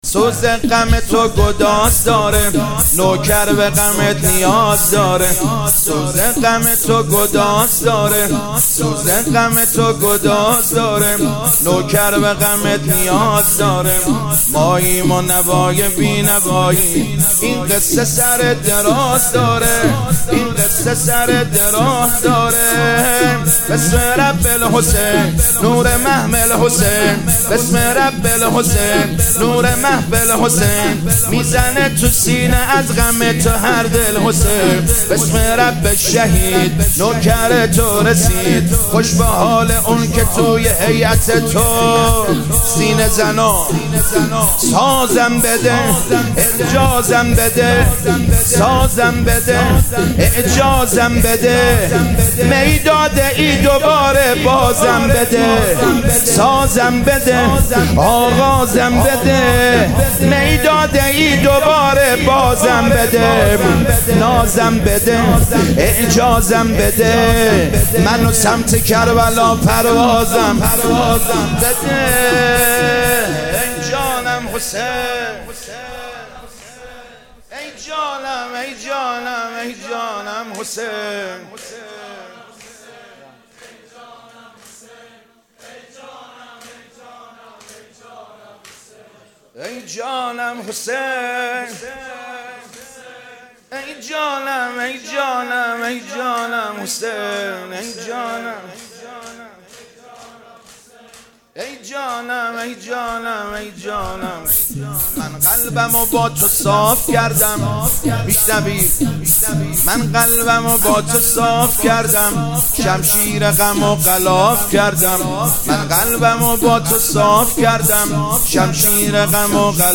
مداحی جدید
مراسم هفتگی هیات کربلا رفسنجان